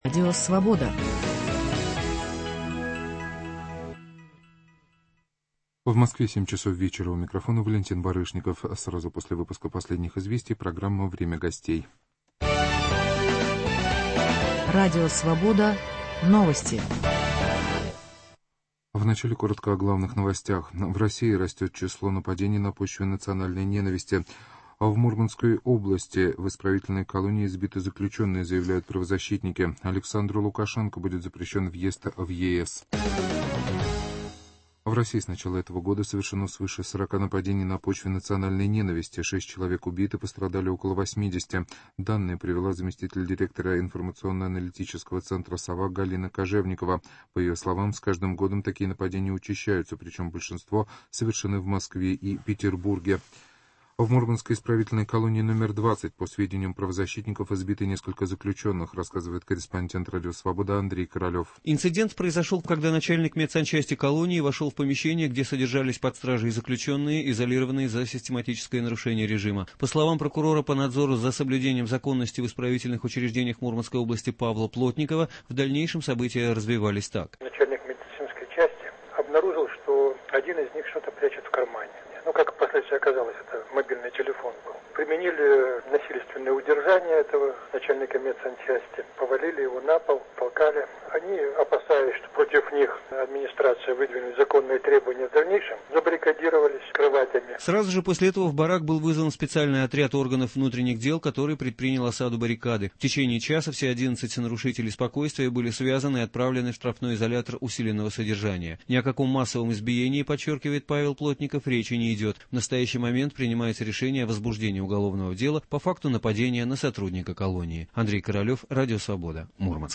О России, об искусстве, о патриотизме, о месте художника в этом мире, о судьбе Петербурга и многом другом в беседе со скульптором, художником, действительным членом Нью-Йоркской академии наук, академиком искусств Европы Михаилом Шемякиным.